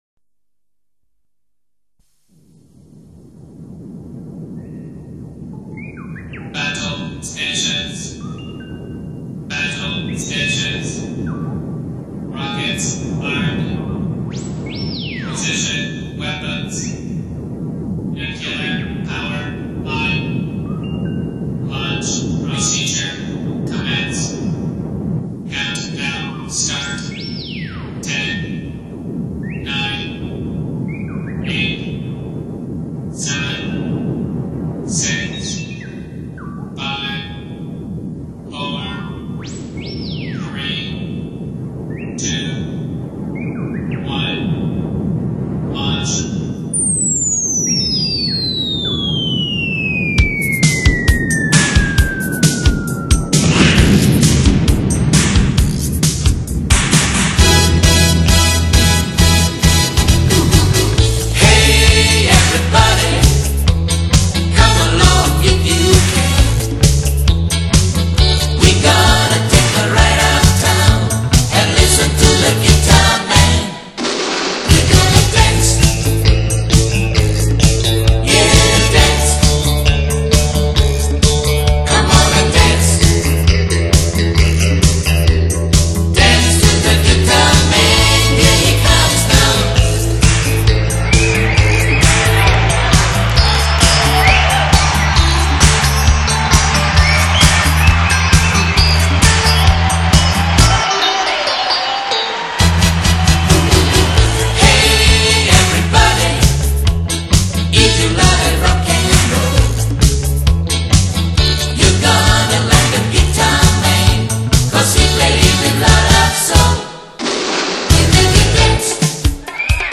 西洋金曲 串烧45转